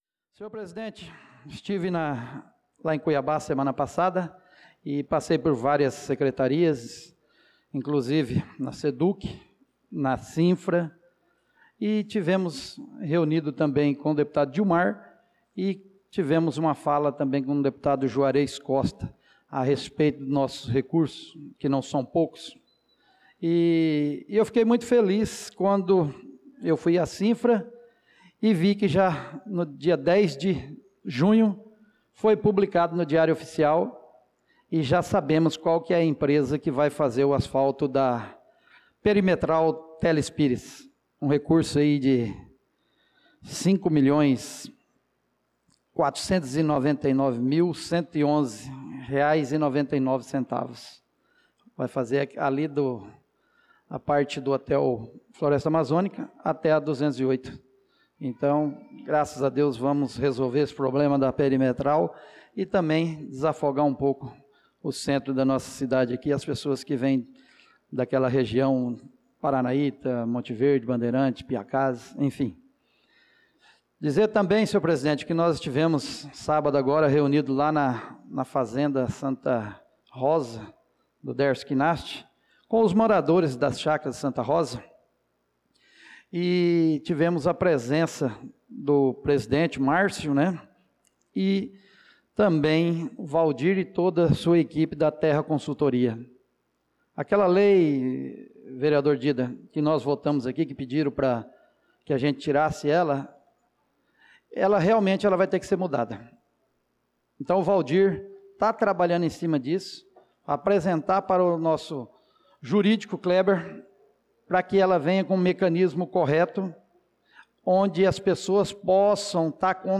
Pronunciamento do vereador Marcos Menin na Sessão Ordinária do dia 16/06/2025